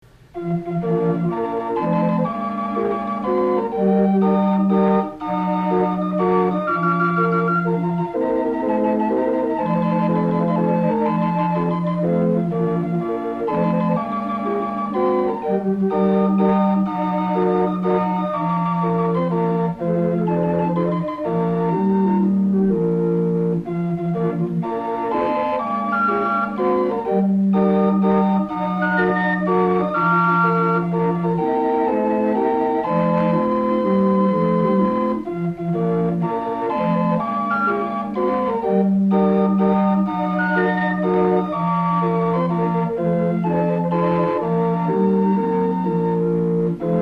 AUTHENTIC CIRCUS CALLIOPE MUSIC